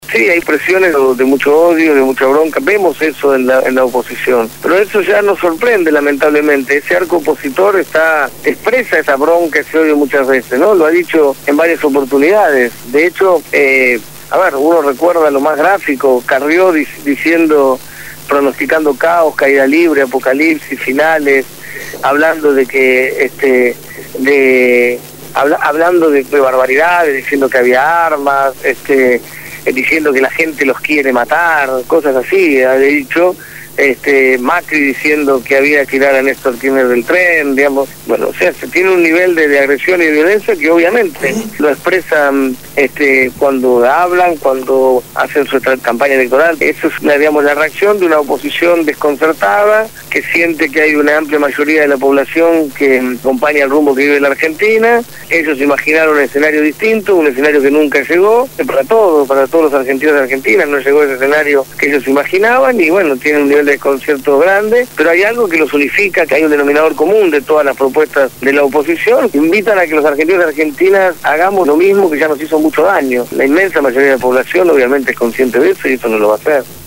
Lo dijo Martín Sabbatella, candidato a gobernador bonaerense por Nuevo Encuentro (cuya boleta llevará la fórmula presidencial Cristina Fernandez-Amado Boudou) en «Desde el Barrio».